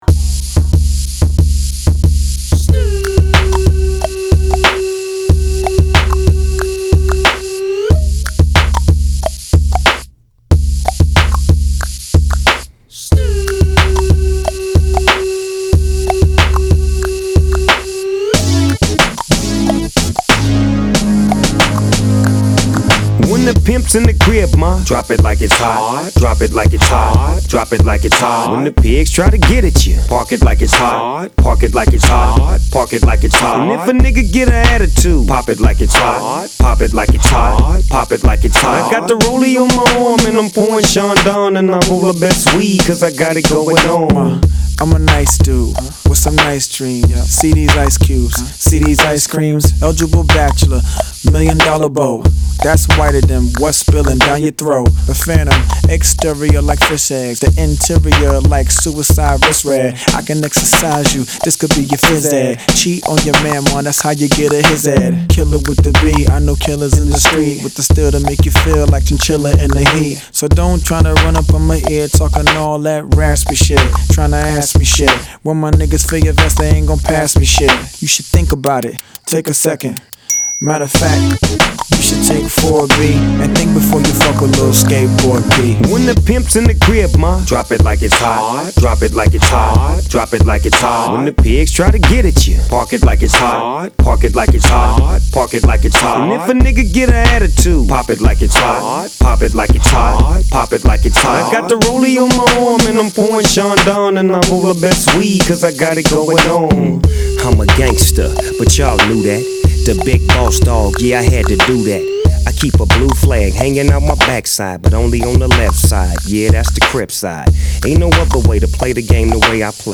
Hip Hop, West Coast Rap, R&B